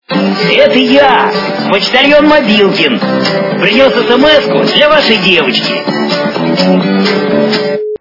- звуки для СМС
Звук Голос - Это я почтальон Мобилкин. Принес смску для вашей девочки